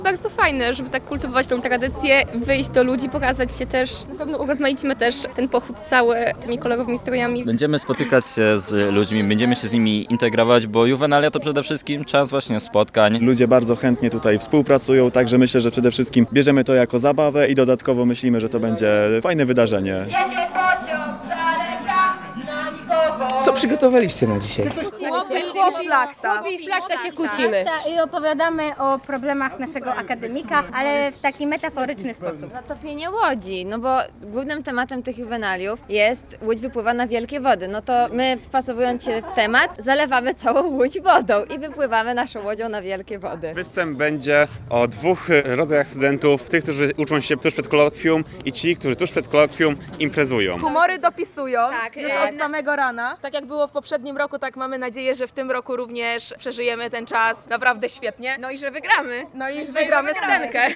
Pochód po osiedlu akademickim Lumumbowo w Łodzi